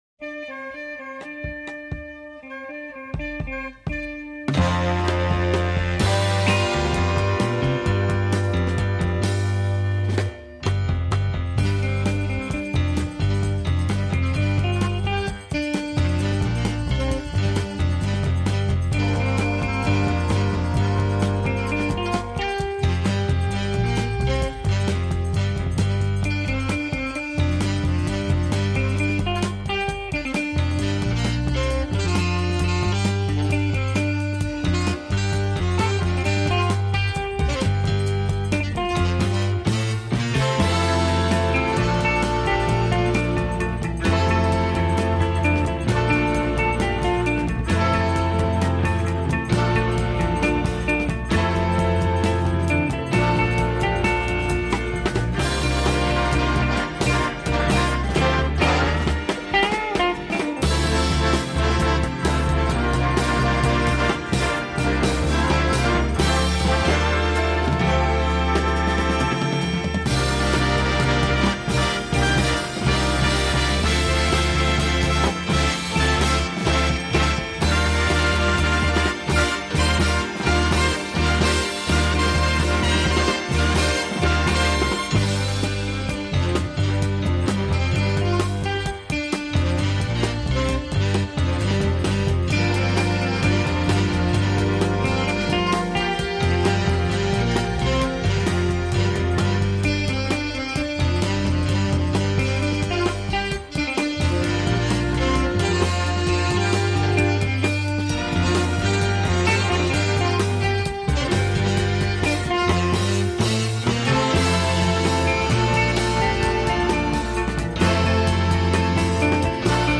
THE REFINED AND BITTERSWEET SOUND OF AN ITALIAN MAESTRO